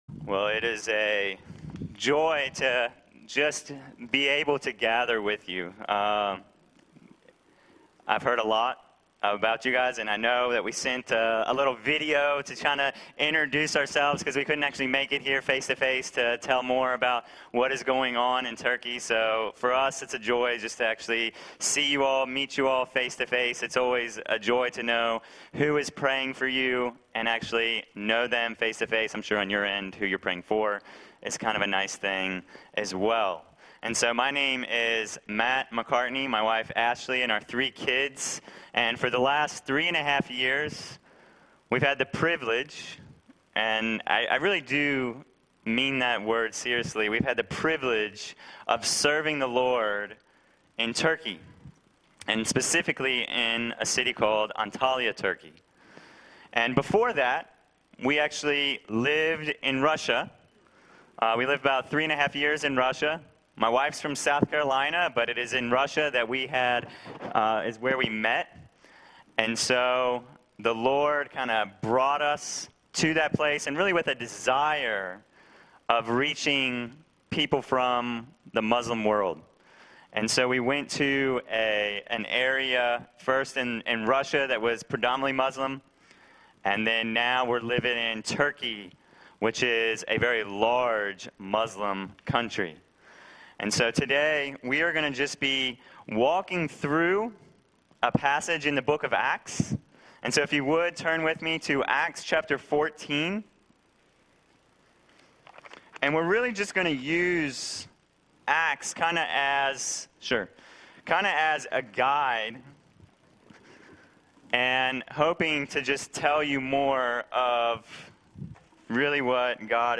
Passage: Acts 14:19-28 Service Type: Sunday Morning « Worship Jesus & Follow Scripture Love People